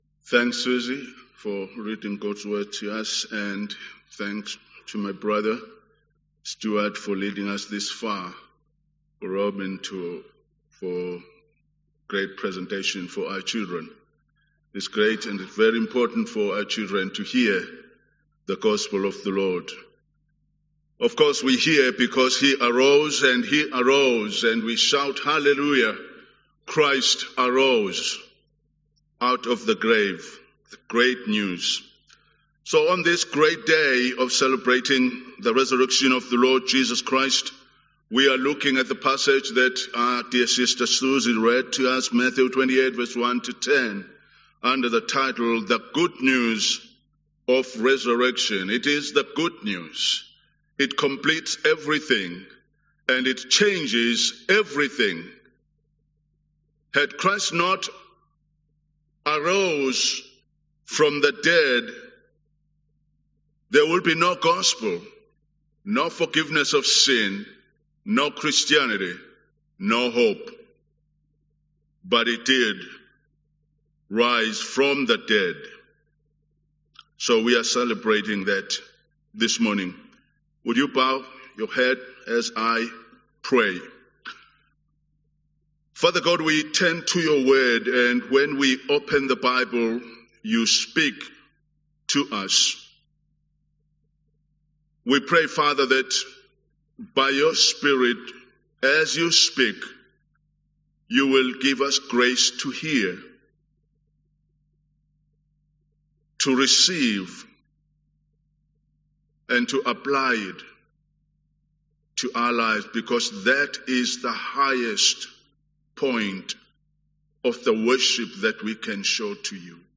English Sermon